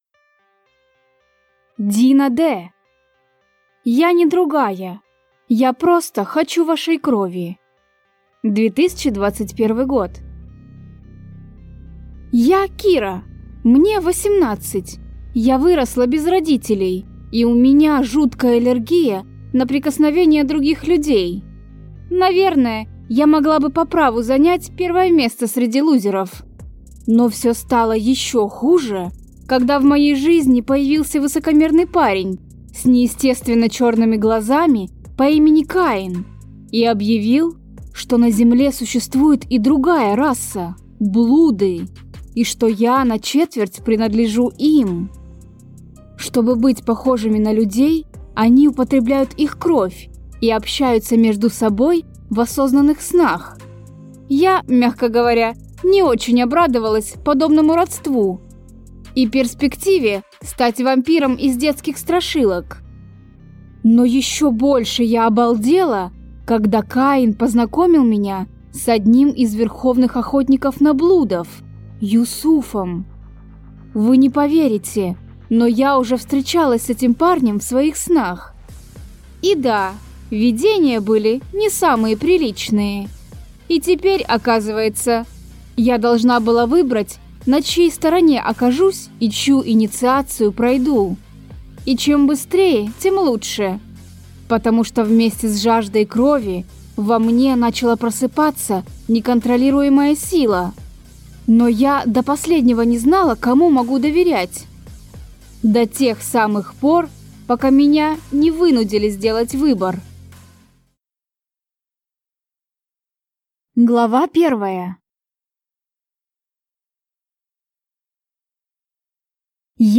Аудиокнига Я не другая. Я просто хочу вашей крови | Библиотека аудиокниг